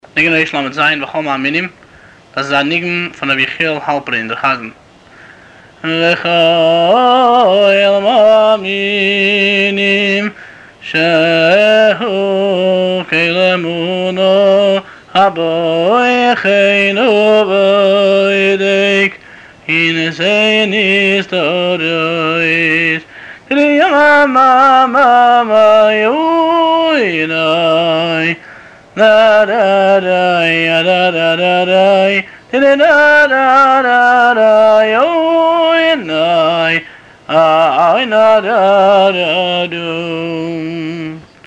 הניגון
הבעל-מנגן